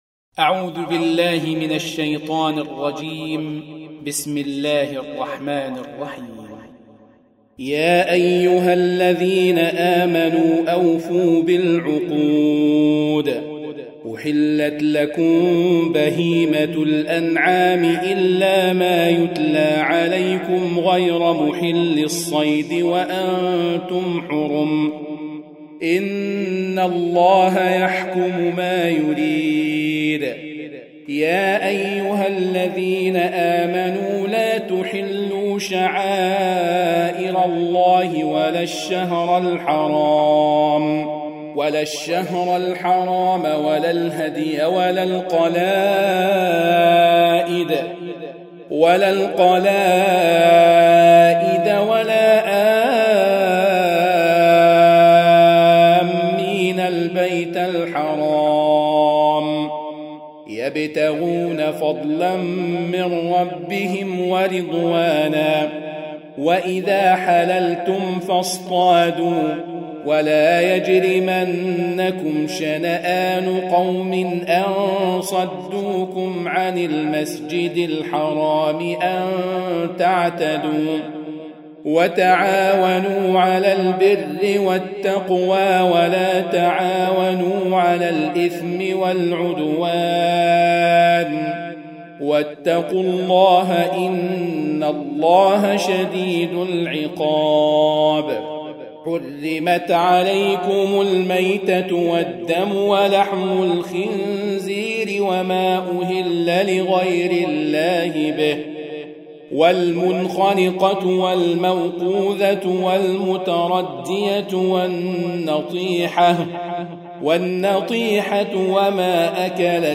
Surah Repeating تكرار السورة Download Surah حمّل السورة Reciting Murattalah Audio for 5. Surah Al-M�'idah سورة المائدة N.B *Surah Includes Al-Basmalah Reciters Sequents تتابع التلاوات Reciters Repeats تكرار التلاوات